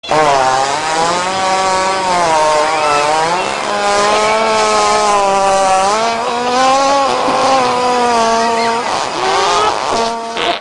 Погрузитесь в мир звуков бегемотов – от громкого рева до забавного фырканья!
Реальный звук: пердёж гиппопотама (пук бегемота)